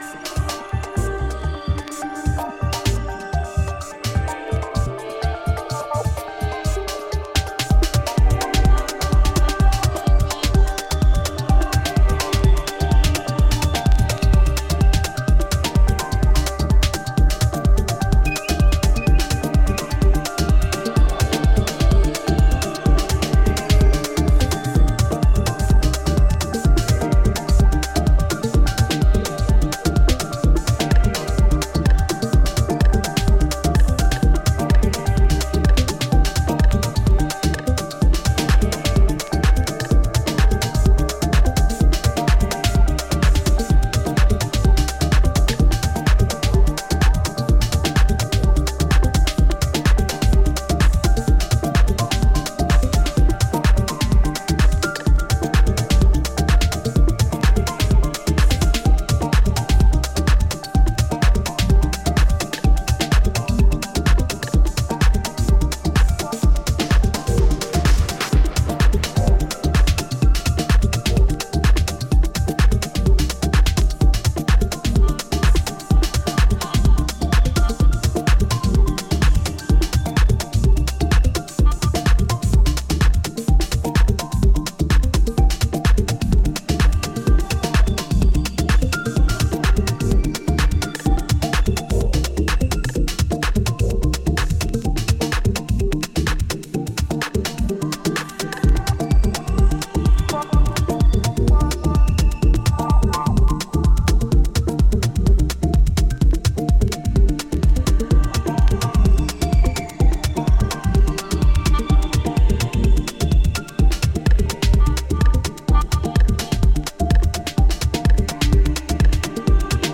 ここでは、抑制の効いたメロディアスでサイケデリックなレイヤーを配し、秀逸なミニマル・テック・ハウスを展開。
リードと浮遊コードがオプティミスティックに響くミニマル・テック